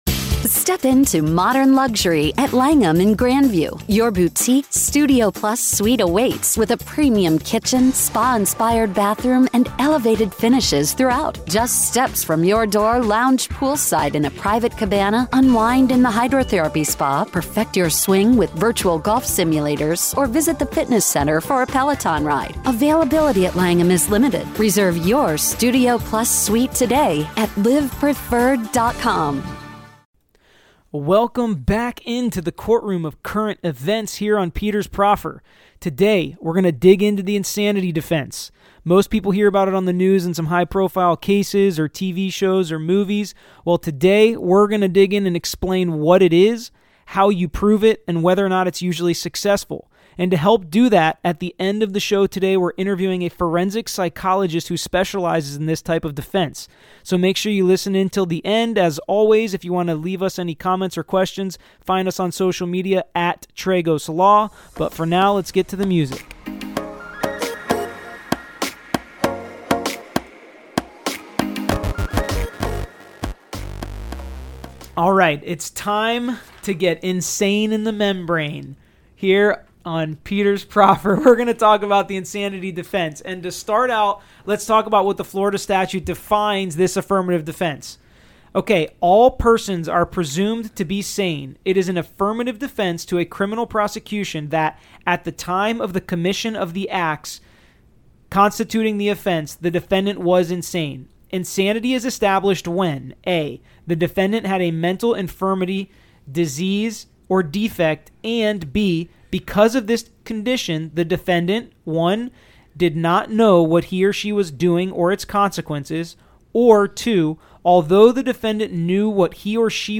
Be sure to listen to the end of the show to hear our interview with forensic psychologist